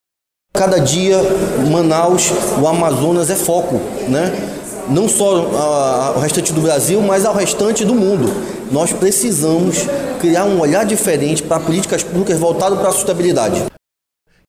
Sonora-2-Lissandro-Breval-–-vereador.mp3